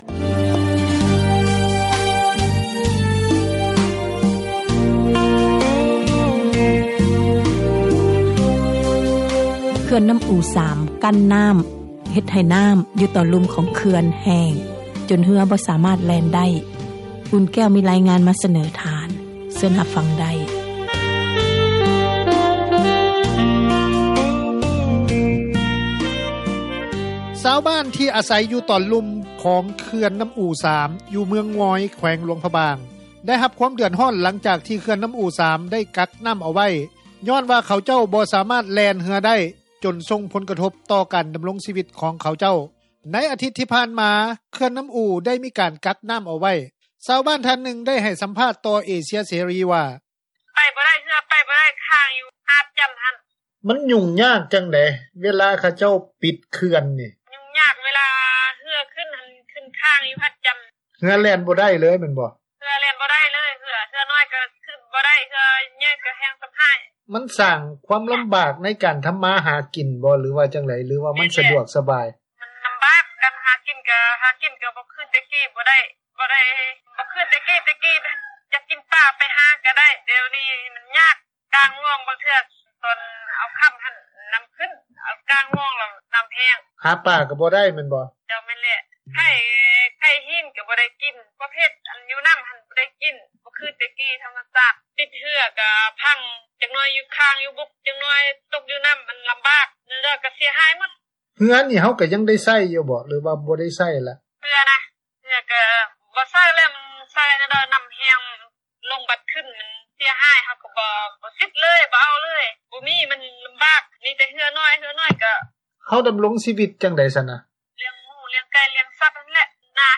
ຊາວບ້ານ ທ່ານນຶ່ງ ໄດ້ໃຫ້ສັມພາດຕໍ່ວິທຍຸ ເອເຊັຽ ເສຣີ ວ່າ:
ຊາວ້ບານ ອີກທ່ານນຶ່ງ ໄດ້ໃຫ້ສັມພາດຕໍ່ວິທຍຸ ເອເຊັຽ ເສຣີ ວ່າ: